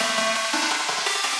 SaS_Arp04_170-E.wav